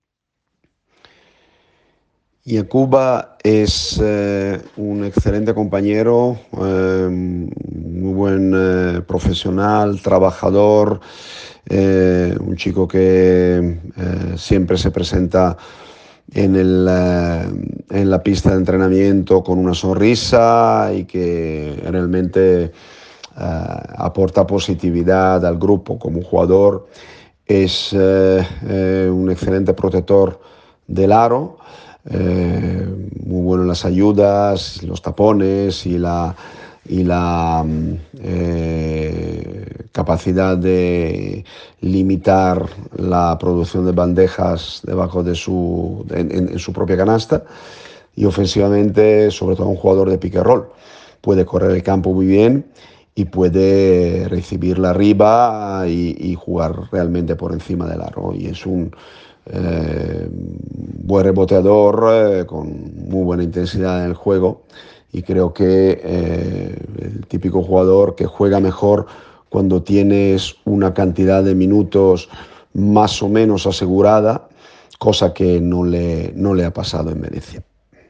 El seleccionador atiende en exclusiva a Radio Marca Málaga y radiografía al nuevo jugador del Unicaja